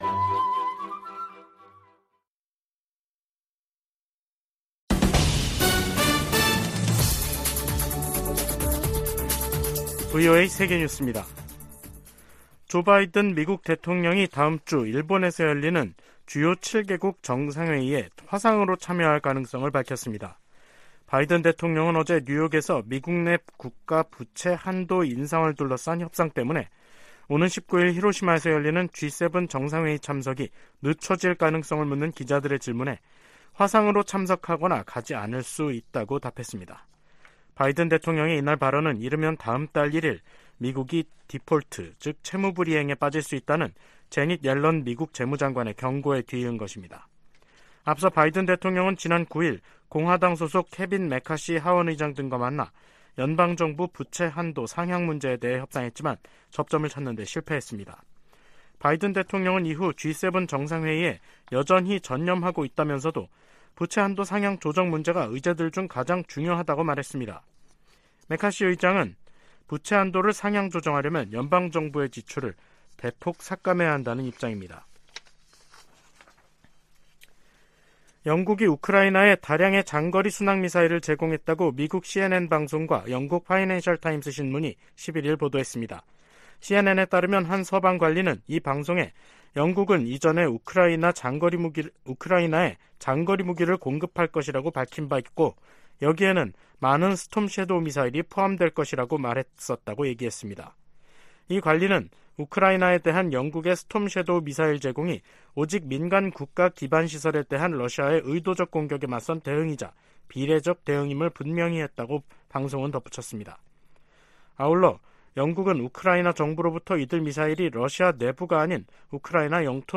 VOA 한국어 간판 뉴스 프로그램 '뉴스 투데이', 2023년 5월 11일 3부 방송입니다. 북한이 사이버 활동으로 미사일 자금 절반을 충당하고 있다고 백악관 고위 관리가 말했습니다. 미한 동맹이 안보 위주에서 국제 도전 과제에 함께 대응하는 관계로 발전했다고 미 국무부가 평가했습니다. 미 국방부가 미한일 3국의 북한 미사일 정보 실시간 공유를 위해 두 나라와 협력하고 있다고 확인했습니다.